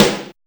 626 SNARE 2.wav